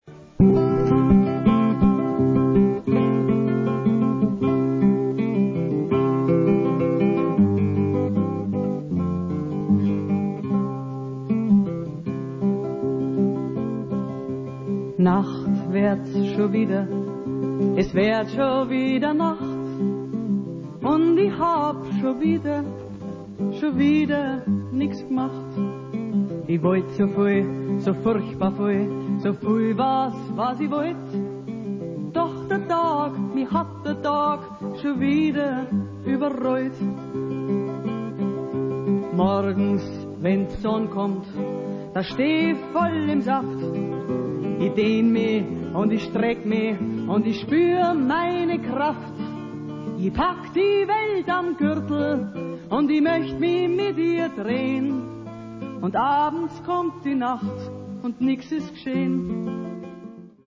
Unter bearbeitet wurde das Rauschen entfernt.